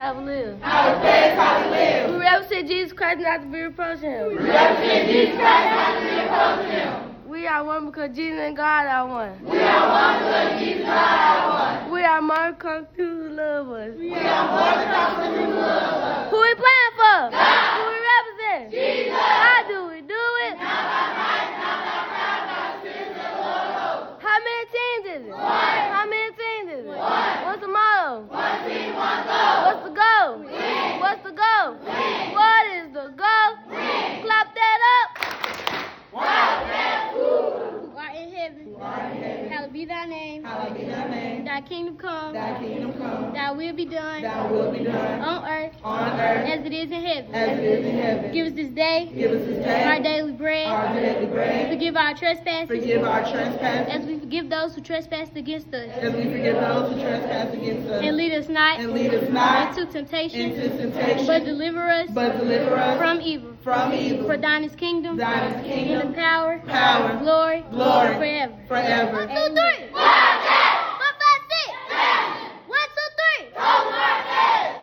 Our Chant
SSW-Chant.m4a